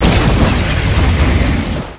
flame.wav